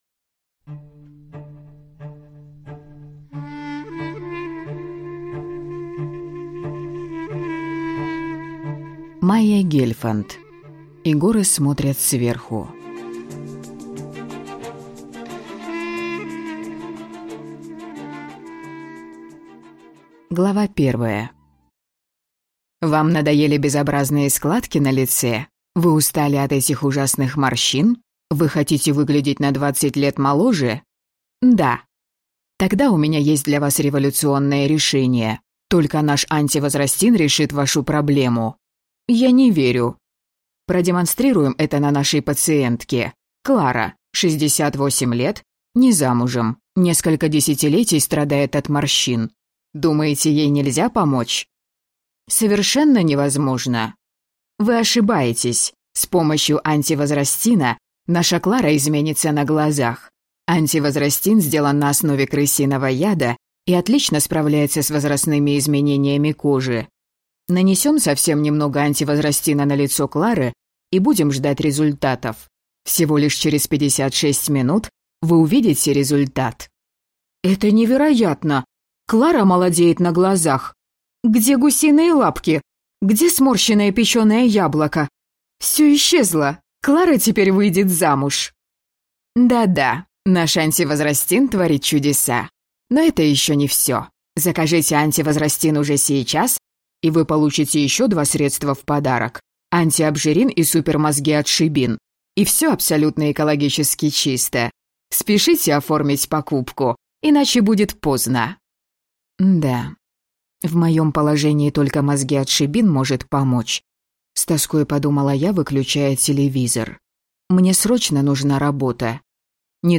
Аудиокнига И горы смотрят сверху | Библиотека аудиокниг